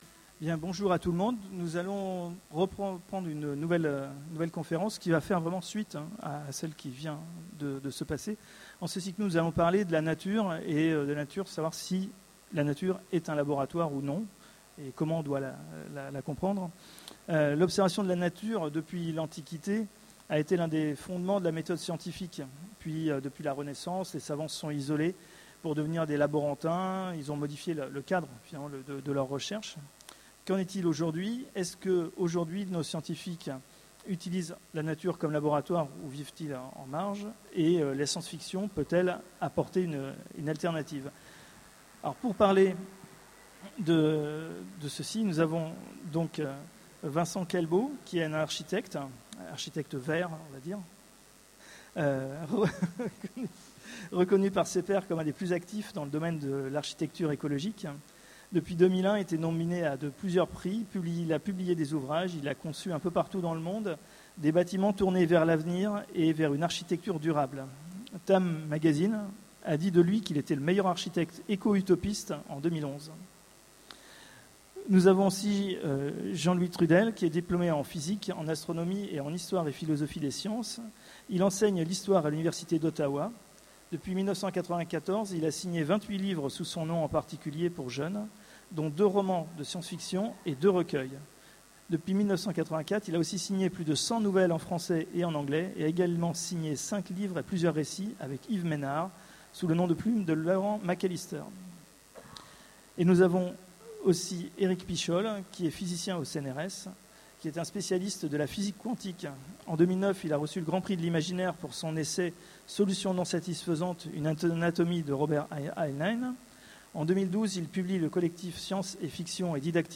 Utopiales 13 : Conférence La nature est-elle un laboratoire ?